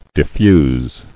[dif·fuse]